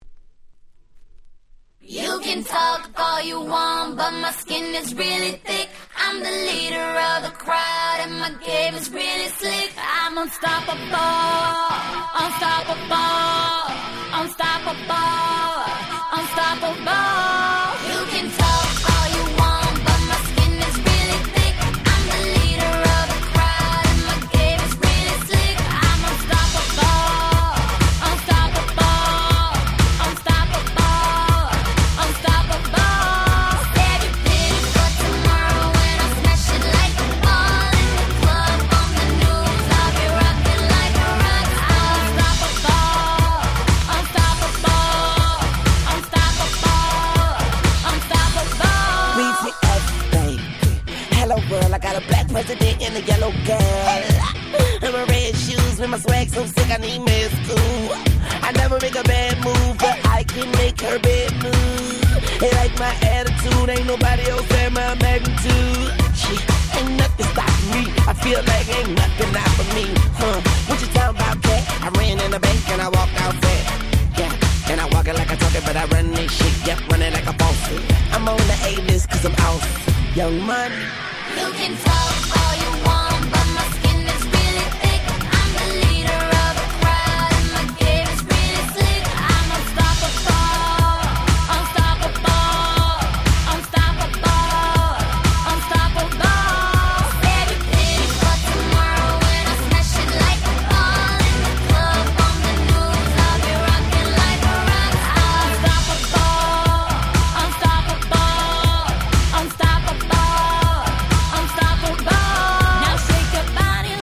09' Smash Hit R&B !!